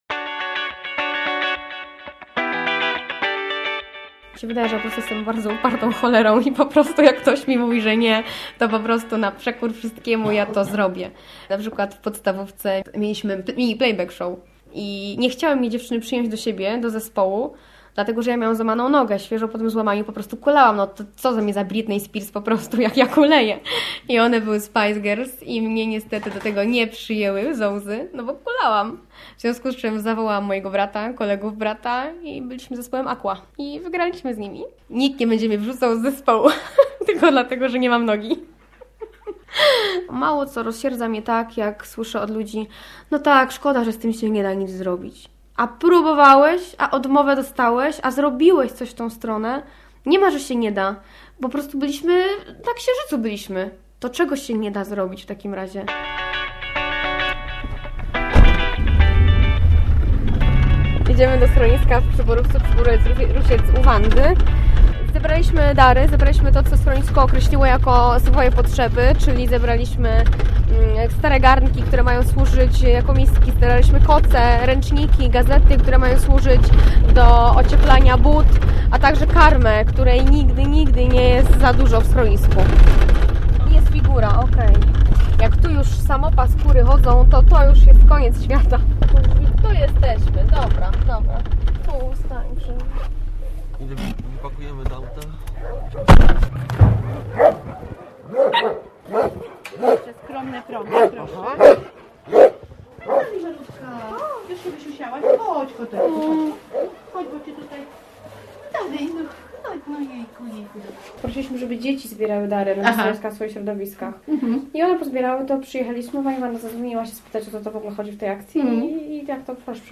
Bakcyl - reportaż